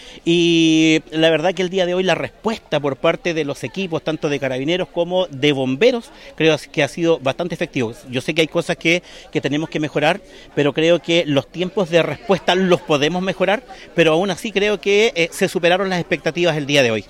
Mientras que, el alcalde de Los Lagos, Víctor Fritz, indicó que si bien, existen cosas por mejorar frente a una situación de emergencia, se superaron las expectativas.